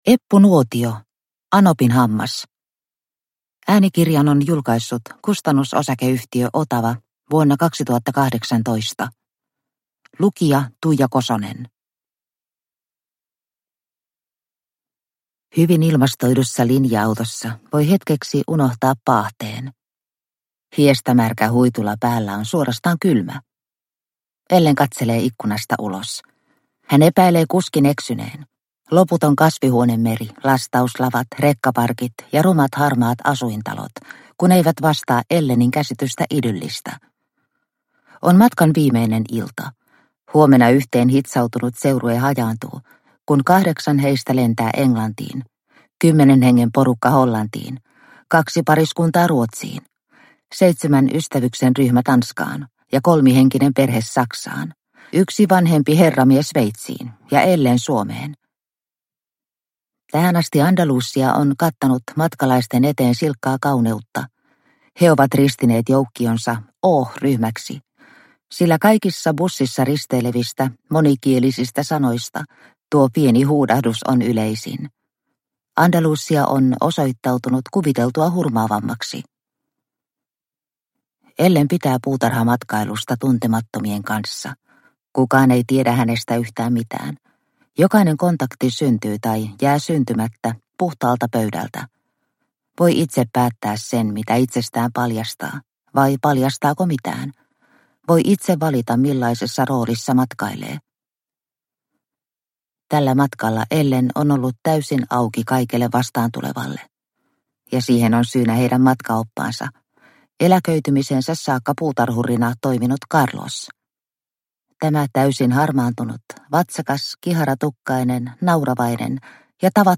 Anopinhammas – Ljudbok – Laddas ner